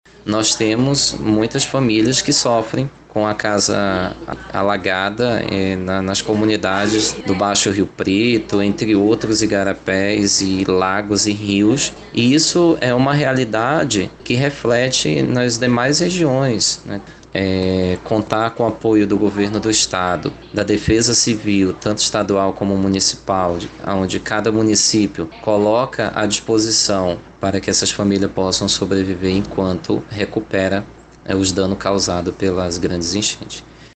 Sonora2_Agricultor.mp3